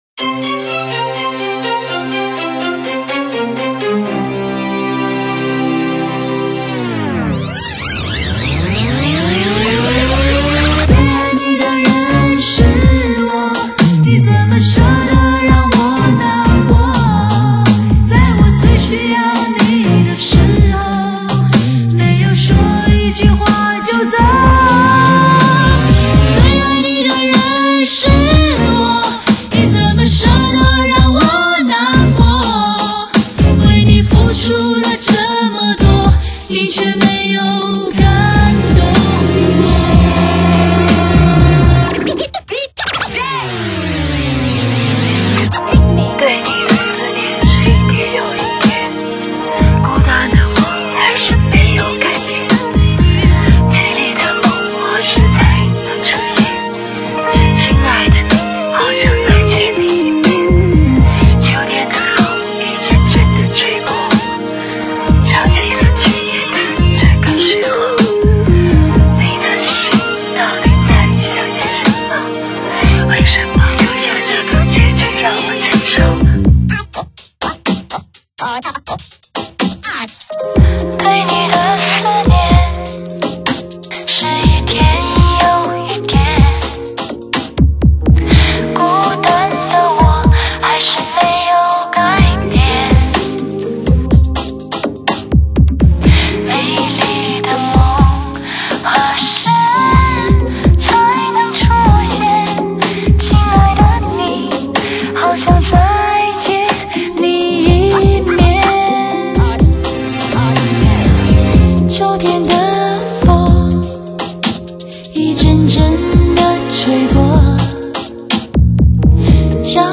很有些空灵的感觉